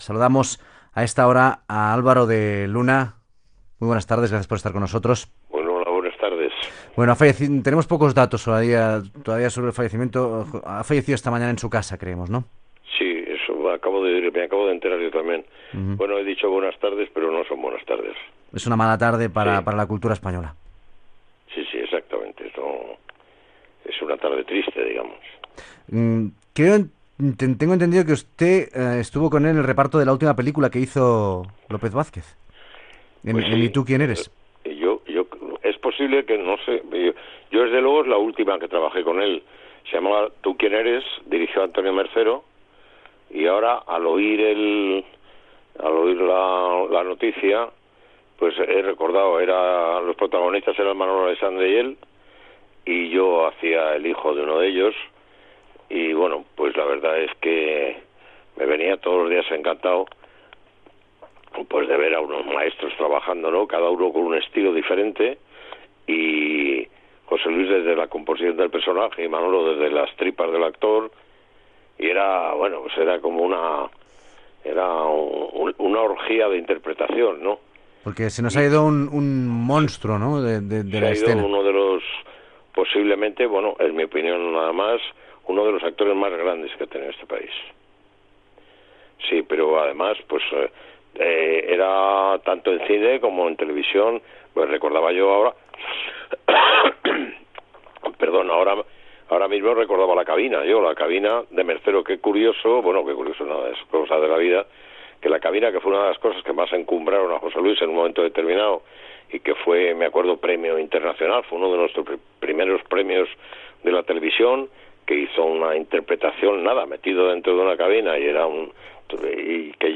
L'actor Álvaro de Luna valora la figura de l'actor José Luis López Vázquez el dia en el qual es va saber que havia mort a Madrid, als 87 anys
Informatiu